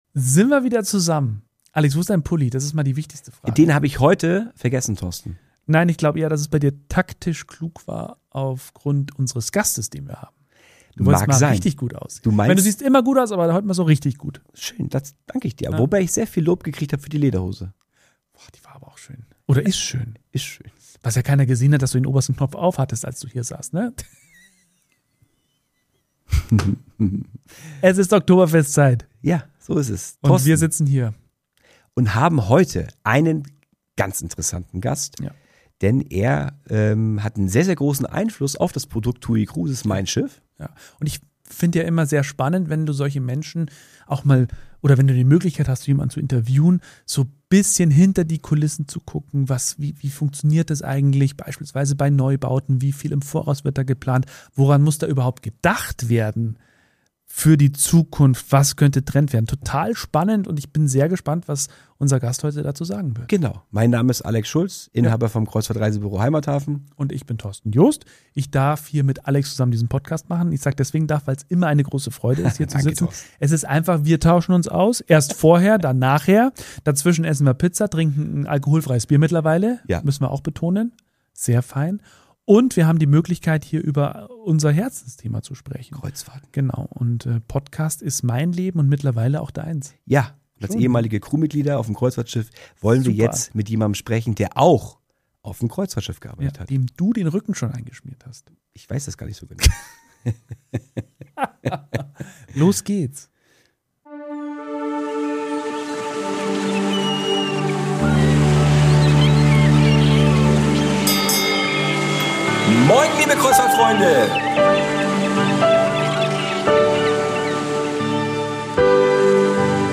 Ein tolles Interview mit vielen Einblicken.